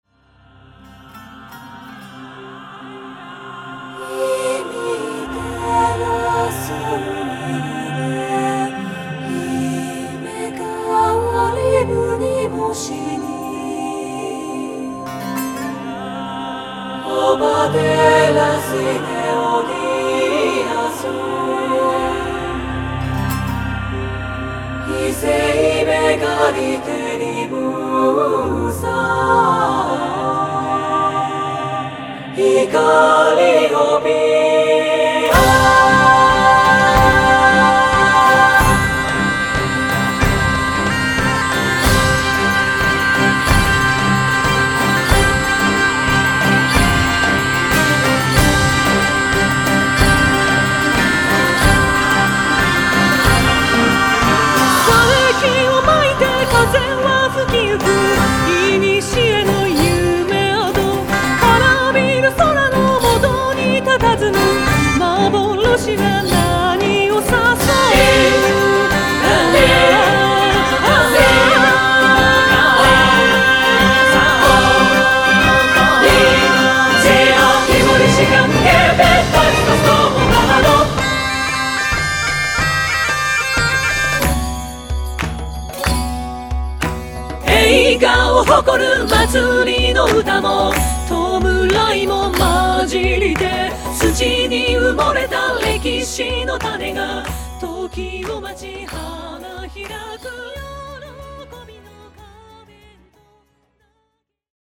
※再生中にノイズや歪みの様に聴こえる箇所がありますが、制作上の意図によるものです。ご了承下さい。